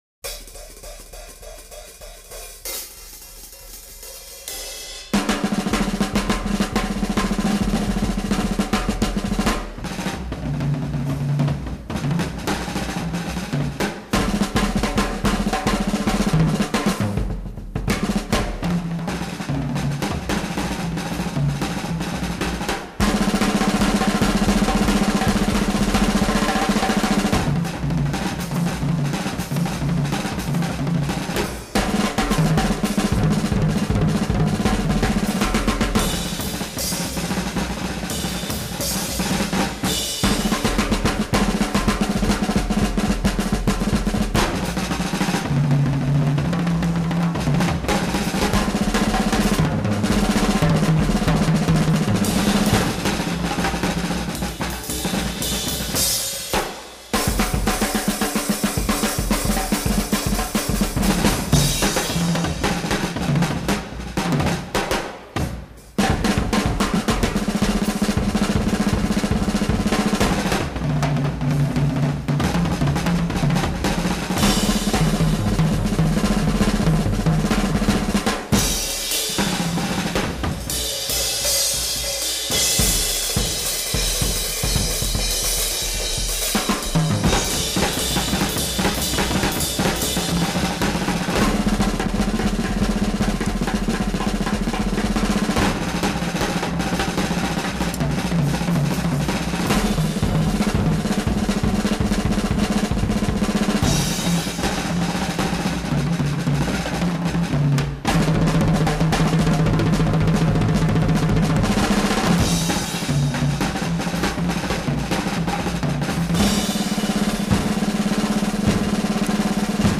drum battle